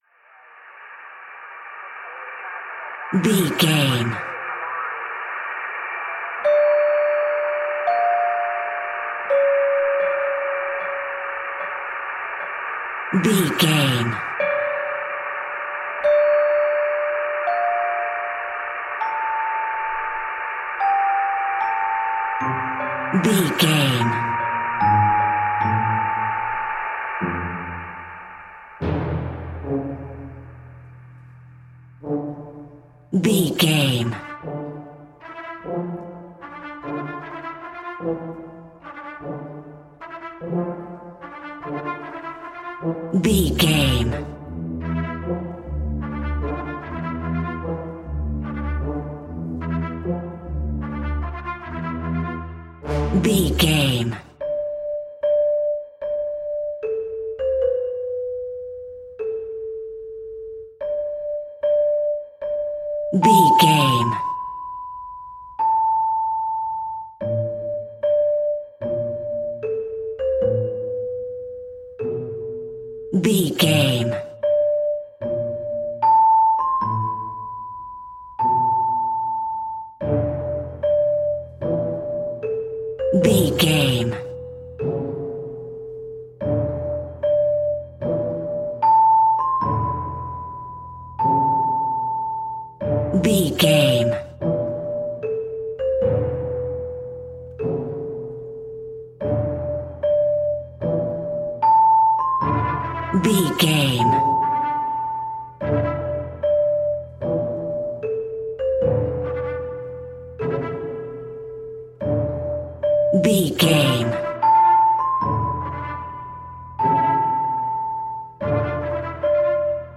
Aeolian/Minor
D
scary
ominous
dark
haunting
eerie
brass
flute
percussion
horror music
Horror Pads
Horror Synths